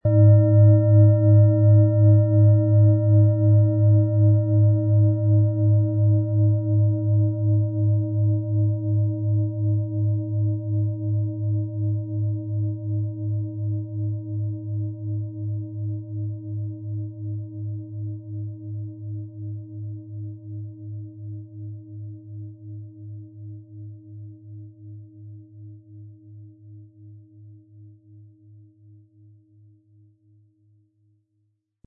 Planetenton
Nach uralter Tradition von Hand getriebene Klangschale.
Unter dem Artikel-Bild finden Sie den Original-Klang dieser Schale im Audio-Player - Jetzt reinhören.
Ihre Klangschale wird mit dem beiliegenden Klöppel schön erklingen.
MaterialBronze